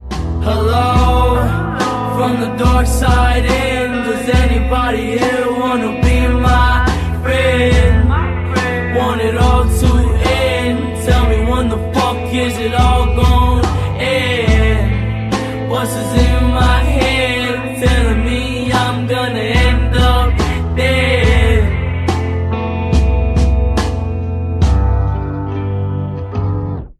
Siempre los últimos tonos de Reguetón